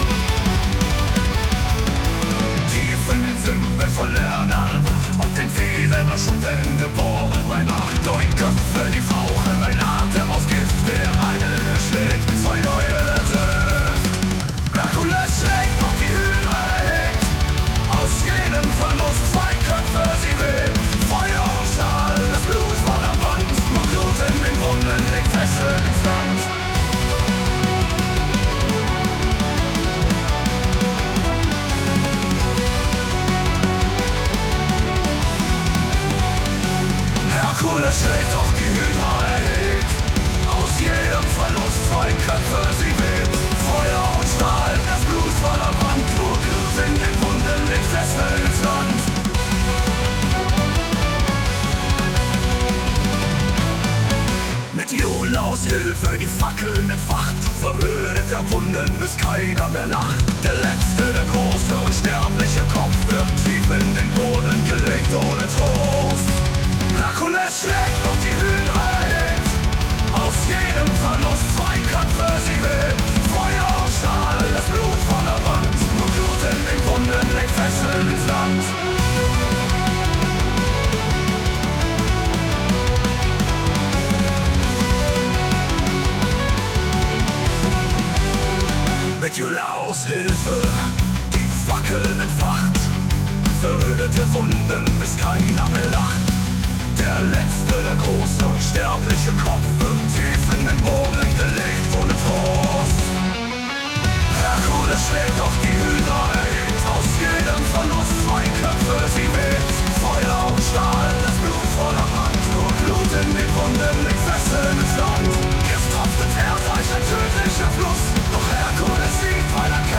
mit ChatGPT getextet und mit SUNO vertont.